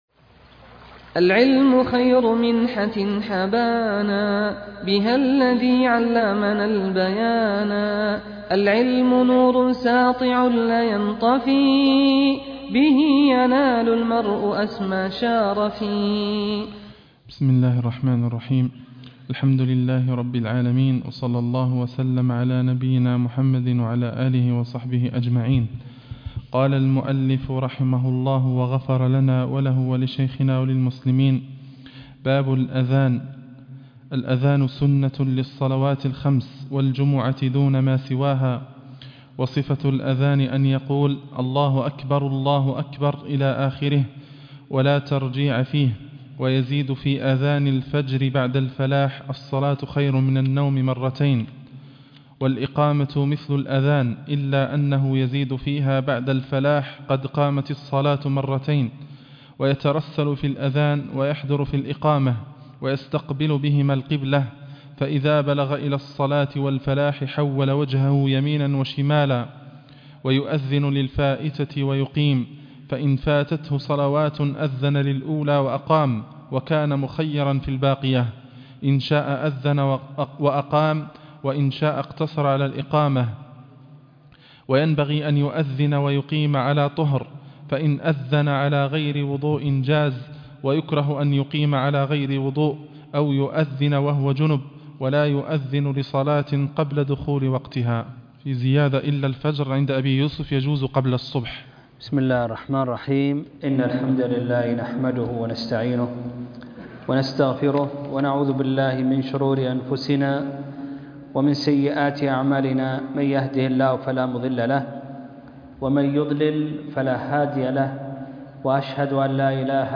الدرس ( 7) الآذان - شرح مختصر القدوري